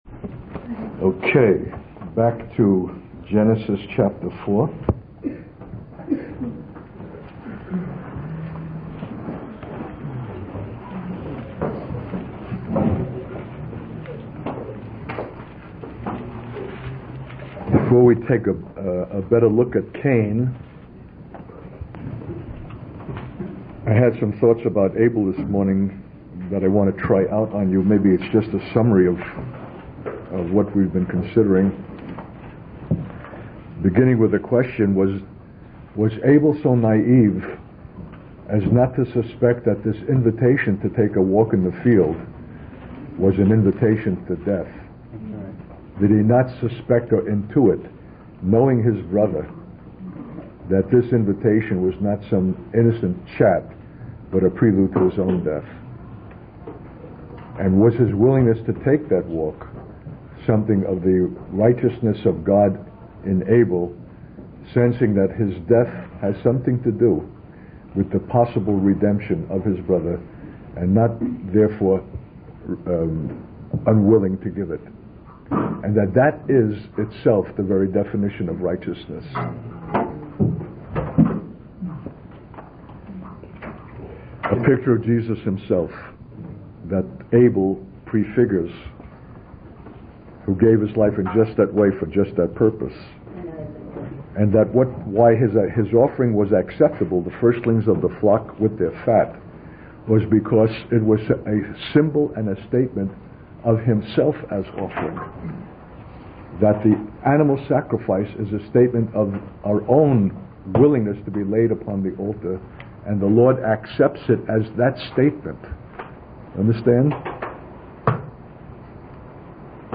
In this sermon, the speaker begins by discussing the story of Cain and Abel from Genesis chapter 4.